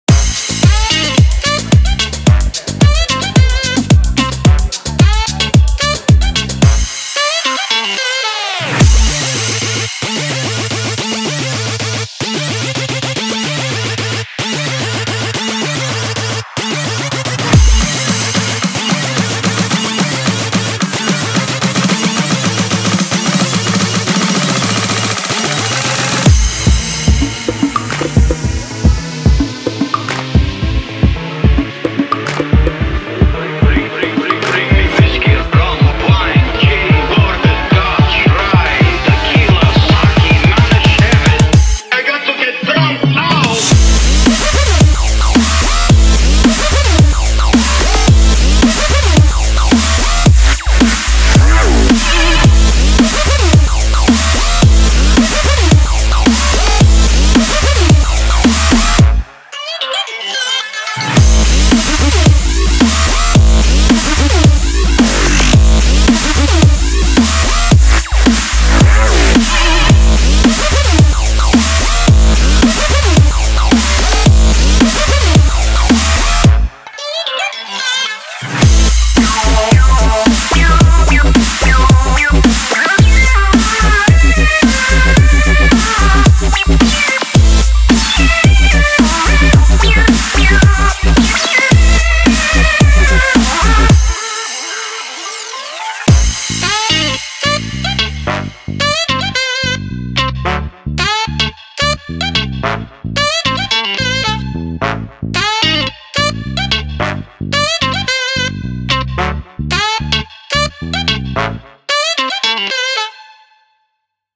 BPM110
Audio QualityPerfect (High Quality)
評論[GLITCH HOP]